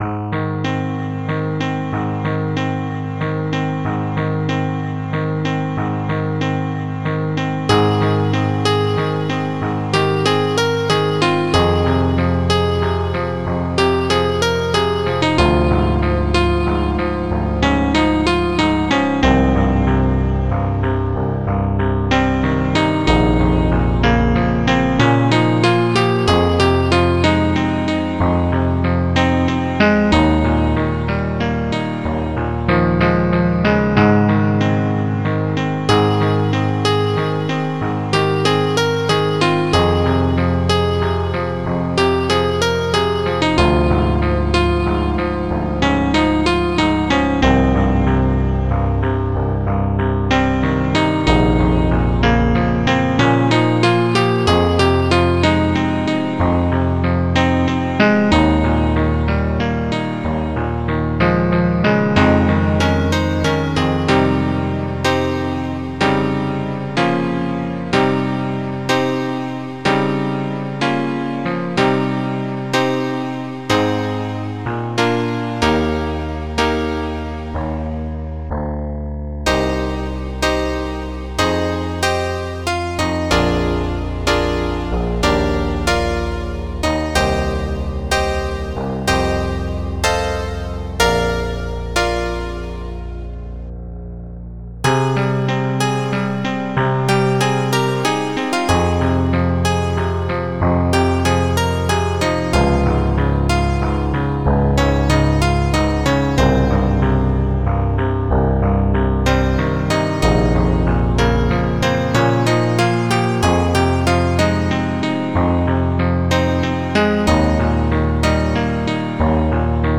Composer 669 Module
Title Memories... Type 669 (Composer 669) Tracker Composer 669 Tracks 8 Samples 1 Patterns 21 Instruments Piano Memories...